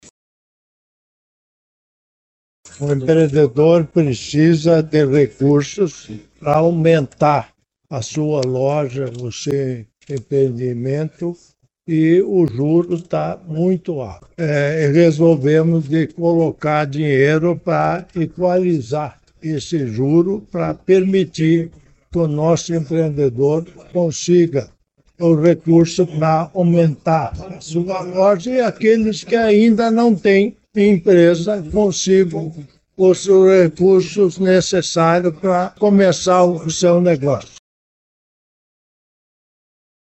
O vice-governador do Paraná, Darci Piana (PSD), destacou a necessidade de recursos aos empreendedores.